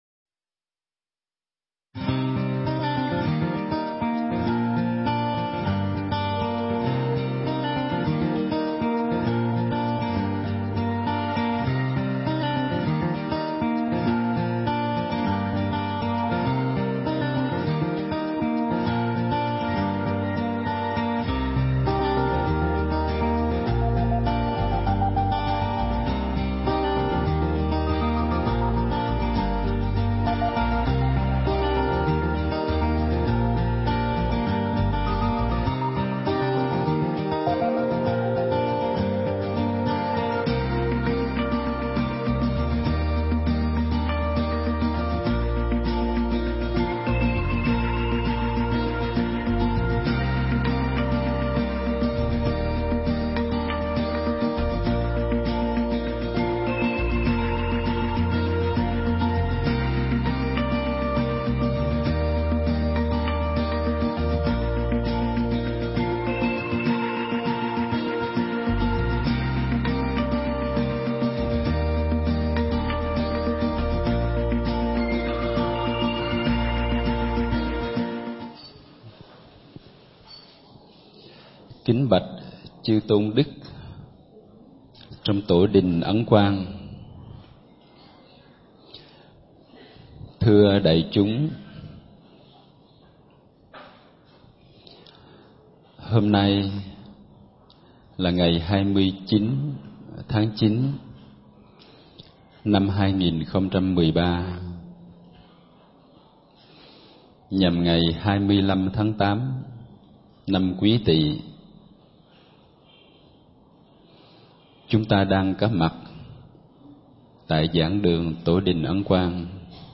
Nghe Mp3 thuyết pháp Bốn Pháp Quán Niệm